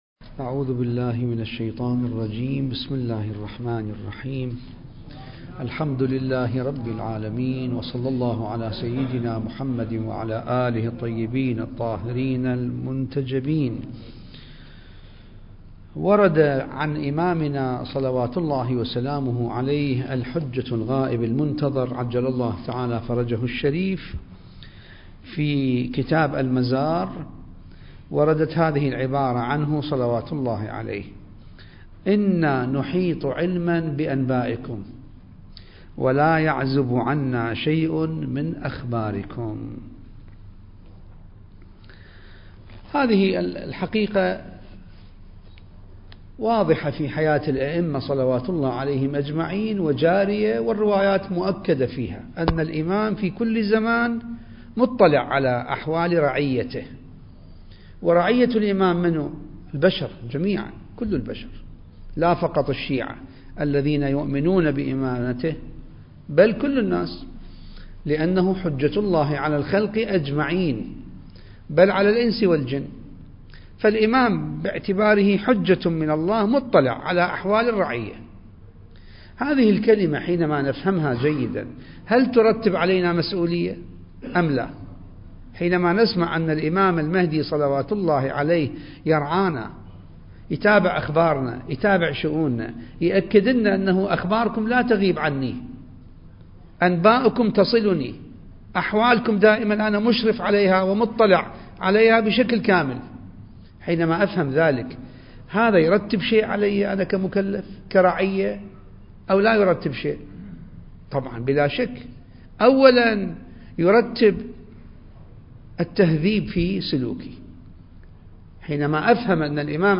المكان: جامع الصاحب (عجّل الله فرجه) - النجف الأشرف التاريخ: 2021